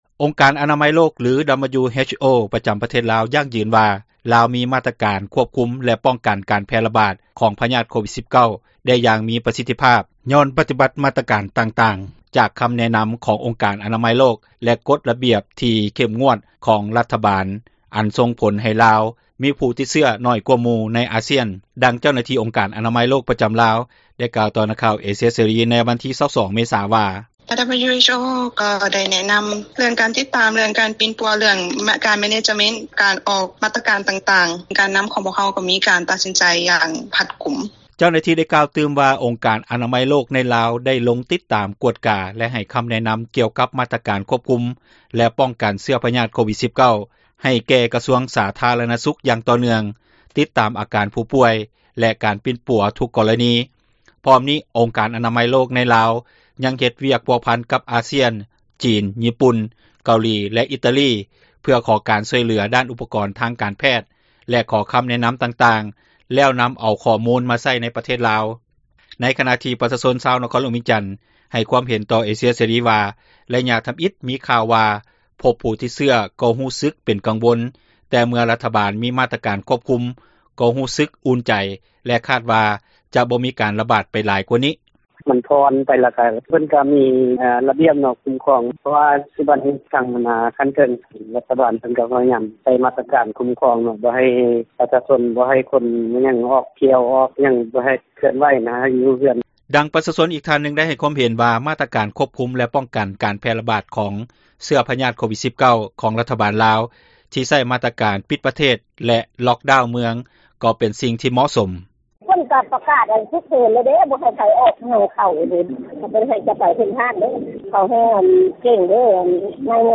ອົງການອະນາມັຍໂລກ ຫລື WHO ປະຈຳປະເທດລາວ ຢັ້ງຢືນວ່າ ລາວ ມີມາຕການຄວບຄຸມ ແລະປ້ອງກັນການແຜ່ຣະບາດຂອງພຍາດ ໂຄວິດ-19 ໄດ້ຢ່າງມີປະສິດຕິພາບ ຍ້ອນປະຕິບັດມາຕການຕ່າງໆ ຕາມຄຳແນະນຳ ຂອງອົງການອະນາມັຍໂລກ ແລະ ກົດຣະບຽບທີ່ເຂັ້ມ ງວດ ຂອງຣັຖບານອັນສົ່ງຜົລໃຫ້ ລາວ ມີຜູ້ຕິດເຊື້ອໜ້ອຍກວ່າໝູ່ໃນອາຊ້ຽນ. ດັ່ງເຈົ້າໜາທີ່ອົງການອະນາມັຍໂລກ ປະຈຳລາວໄດ້ກ່າວຕໍ່ ນັກຂ່າວເອເຊັຽເສຣີ ໃນວັນທີ 22 ເມສາ ວ່າ:
ໃນຂນະທີ່ປະຊາຊົນຊາວນະຄອນຫລວງວຽງຈັນ ໃຫ້ຄວາມເຫັນຕໍ່ເອເຊັຽເສຣີວ່າ ໄລຍະທຳອິດ ມີຂ່າວວ່າພົບຜູ້ຕິດເຊື້ອ ກໍຮູ້ສຶກເປັນກັງວົນ ແຕ່ເມື່ອຣັຖບານ ມີມາຕການຄວບຄຸມ ກໍ່ຮູ້ສຶກອຸ່ນໃຈ ແລະ ຄາດວ່າ ຈະບໍ່ມີການຣະບາດໄປ ຫລາຍກວ່ານີ້.